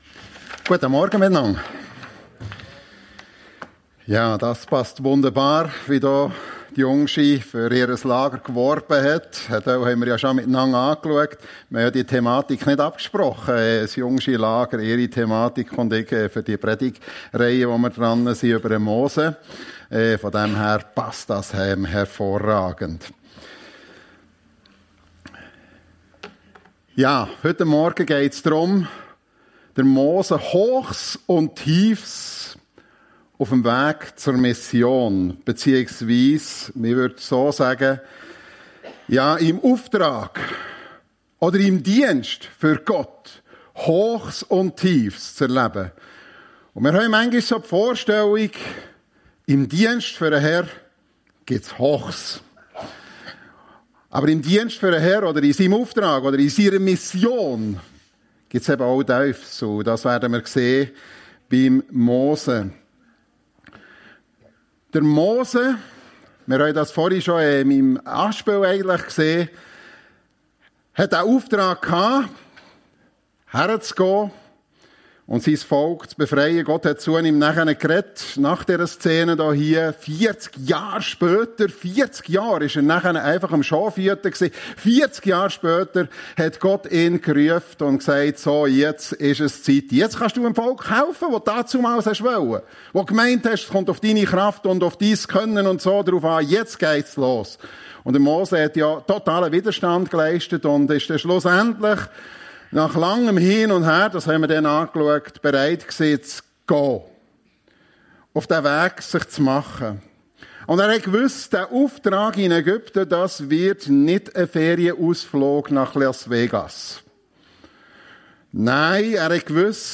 Mose - Hochs und Tiefs auf dem Weg zur Mission ~ FEG Sumiswald - Predigten Podcast